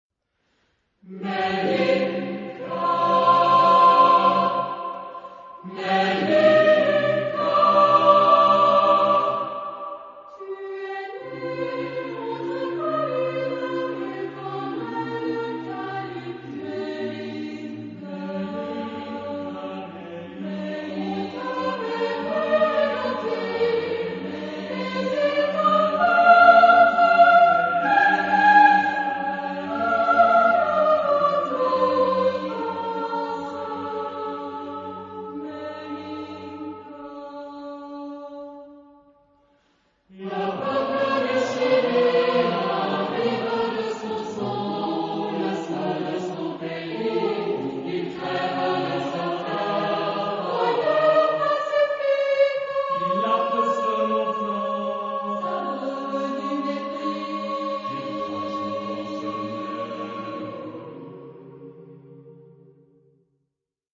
Genre-Style-Forme : Profane ; Contrapuntique ; contemporain
Caractère de la pièce : revendicatif ; dramatique
Tonalité : do mineur